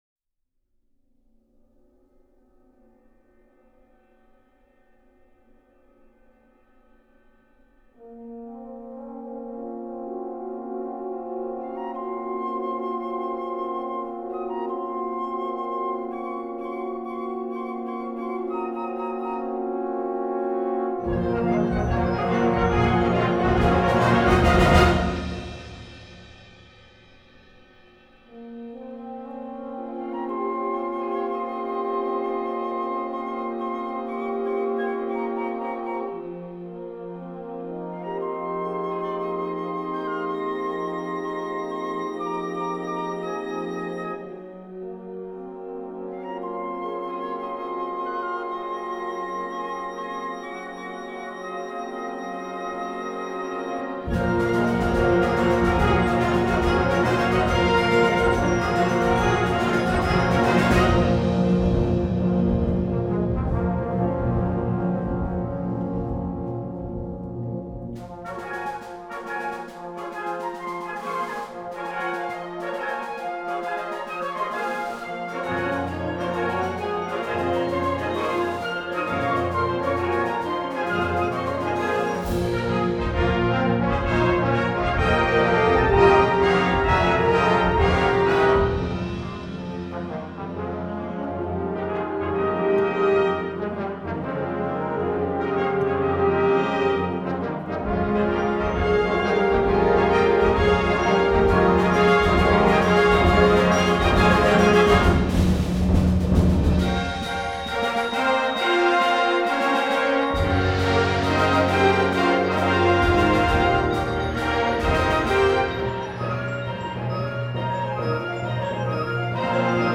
編成：吹奏楽